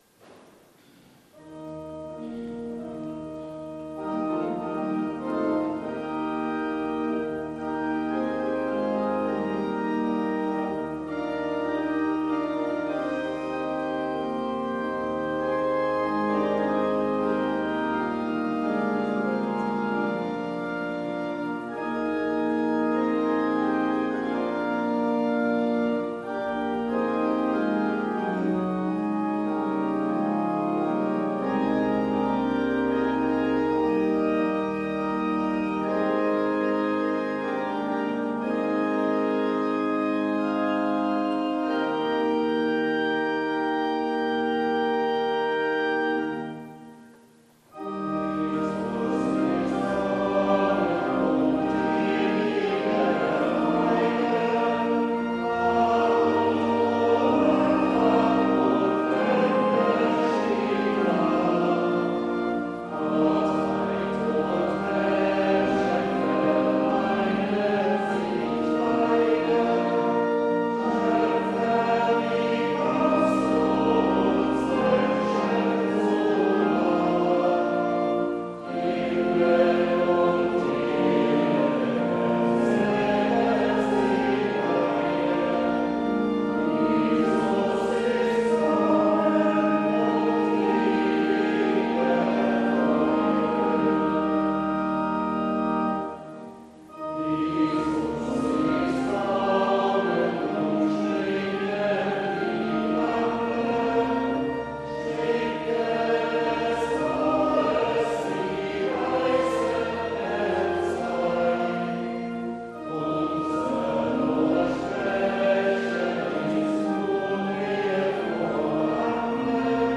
Jesus ist kommen... (LG 277,1-4) Evangelisch-Lutherische St. Johannesgemeinde
Audiomitschnitt unseres Gottesdienstes vom 3. Sonntag nach Trinitatis 2022.